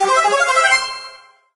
slug_good_kill_01.ogg